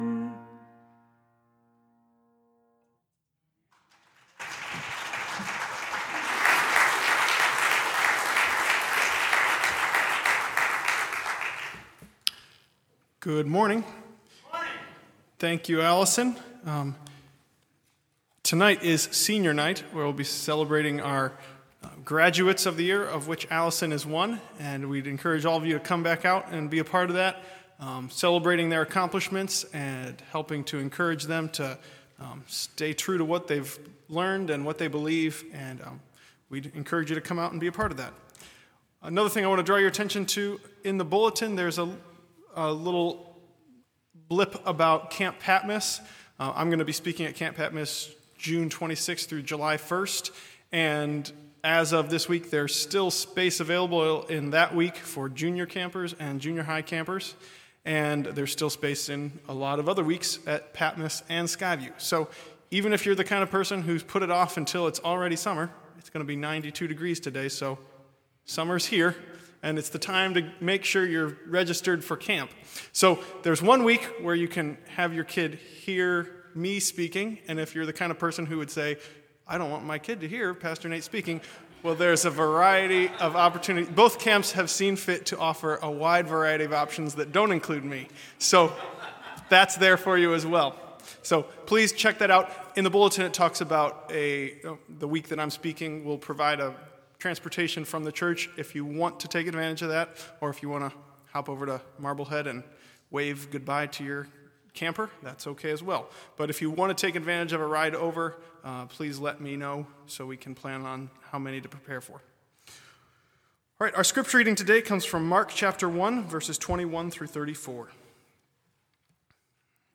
Bible Text: Mark 1:21-34 | Preacher